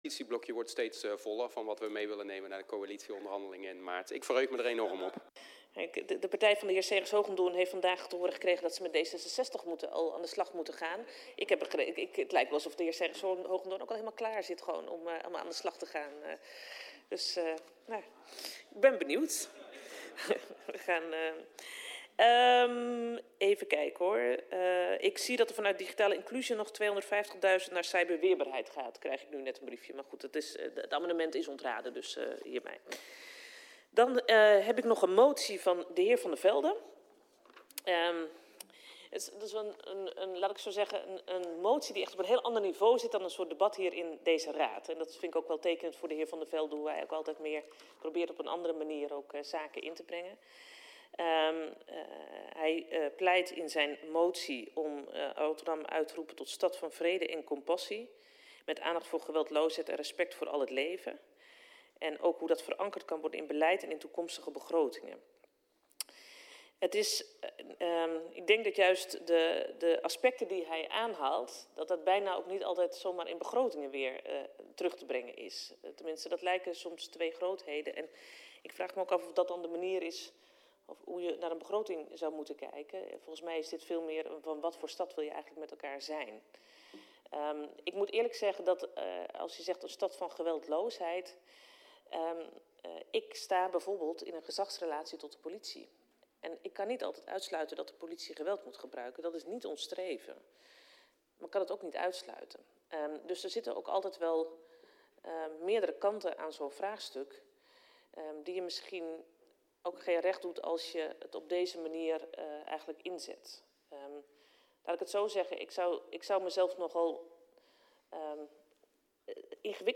Locatie Raadzaal Voorzitter C.J. (Carola) Schouten Toelichting De laatste tien minuten van de uitzending ontbreken in het videobestand.